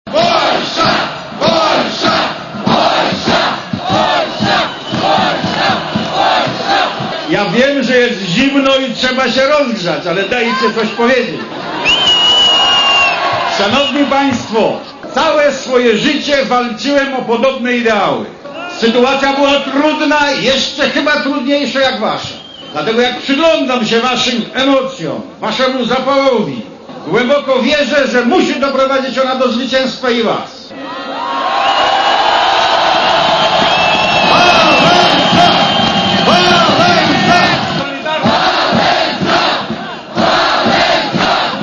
Polsza, Polsza - takie okrzyki i olbrzymia owacja powitały na Placu Niepodległości w Kijowie historycznego przywódcę Solidarnosci.
Lech Wałęsa na wiecu w Kijowie